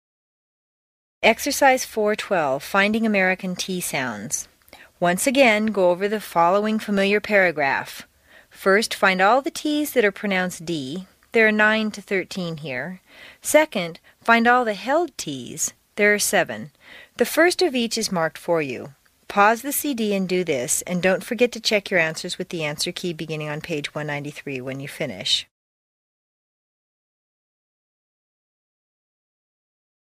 在线英语听力室美式英语正音训练第60期:练习4(12)的听力文件下载,详细解析美式语音语调，讲解美式发音的阶梯性语调训练方法，全方位了解美式发音的技巧与方法，练就一口纯正的美式发音！